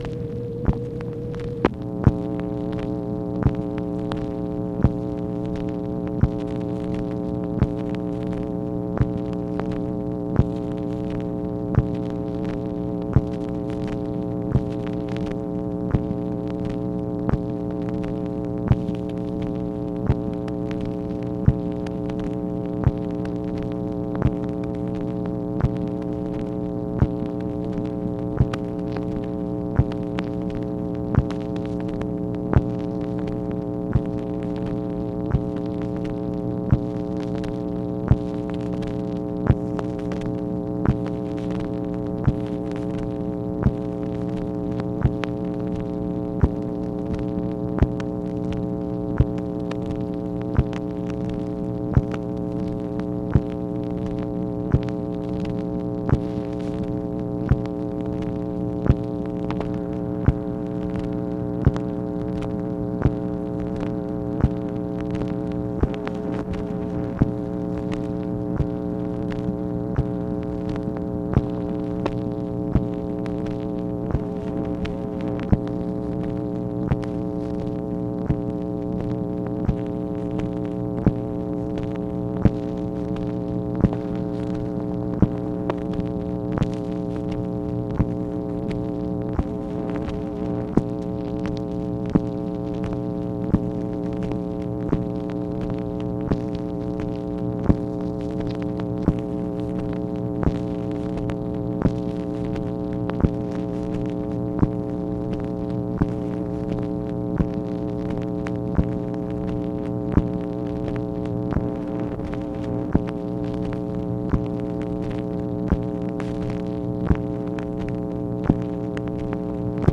MACHINE NOISE, May 14, 1965
Secret White House Tapes | Lyndon B. Johnson Presidency